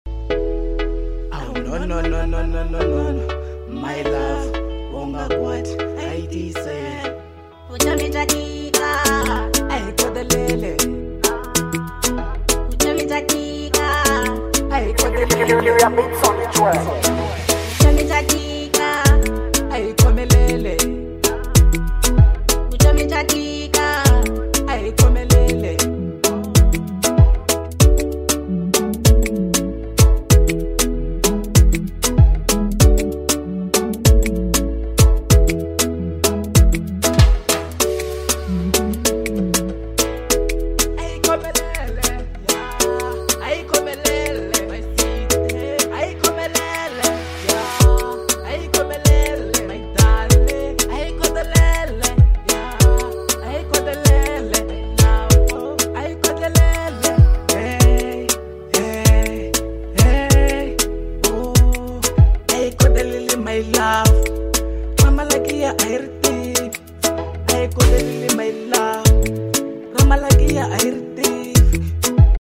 01:12 Genre : Afro Pop Size